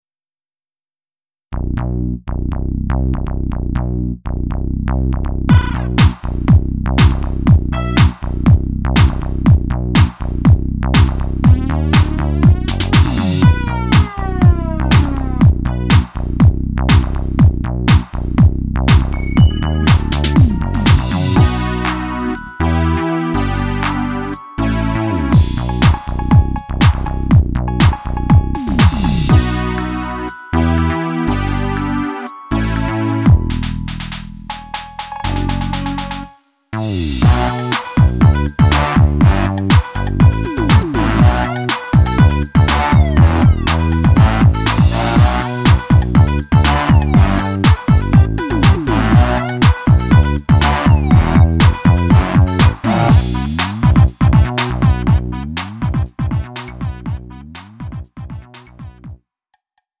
음정 원키 2:50
장르 가요 구분